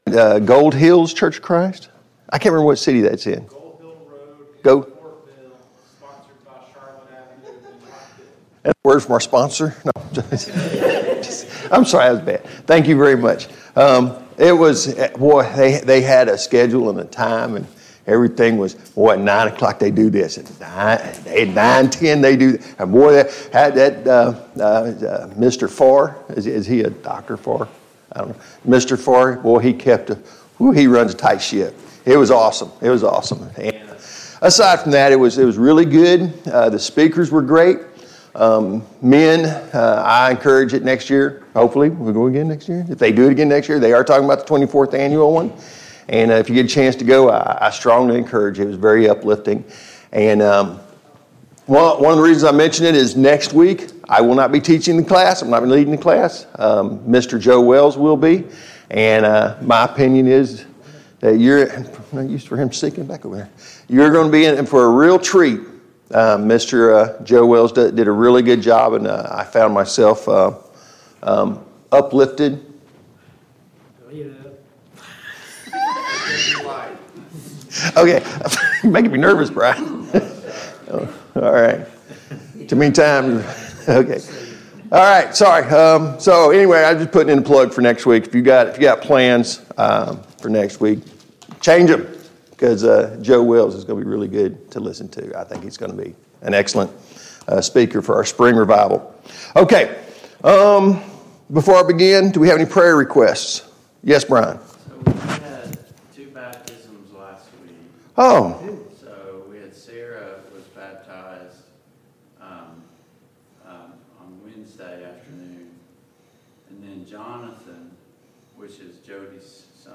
Study on the Parables Service Type: Sunday Morning Bible Class Topics: The Parable of the Sower « 68.